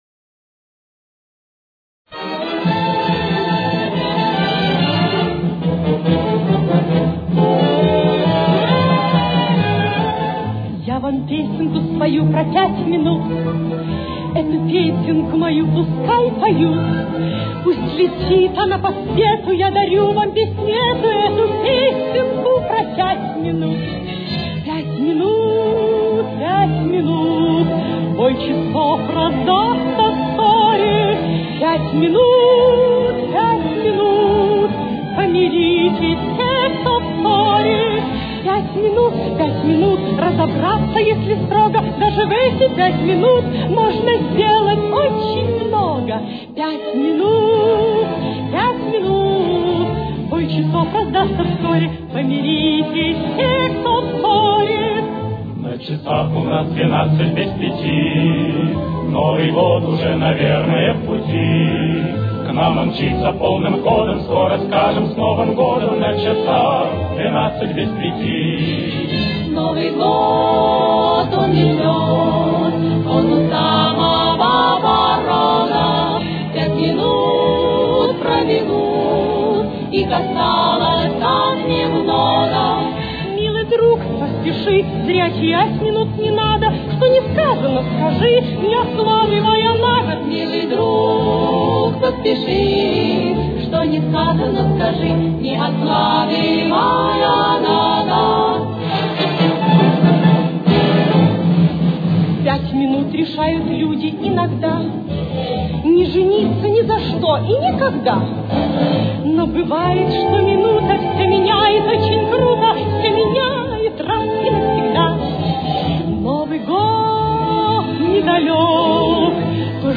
Ля минор. Темп: 143.